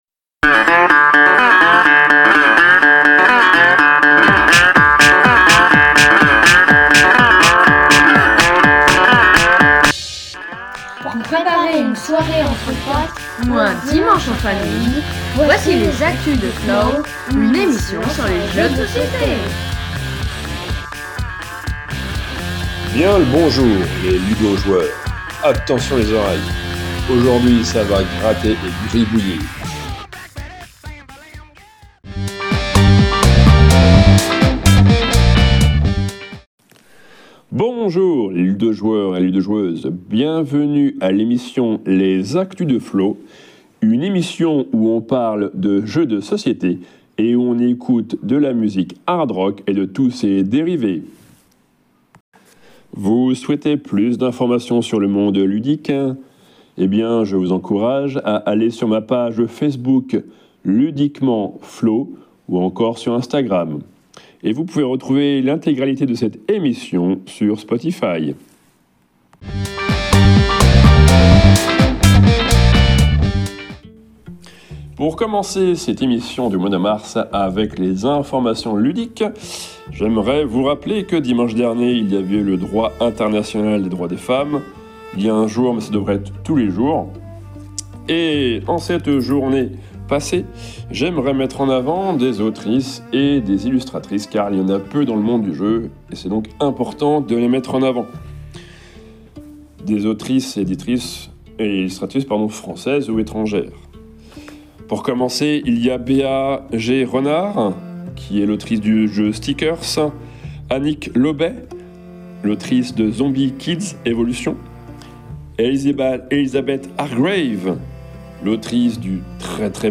Une émission qui parle jeux de société et où on écoute de la musique hard rock et de tous ses dérivés .Ce lois ci , on parle de l'as d'or et de jeux de déduction.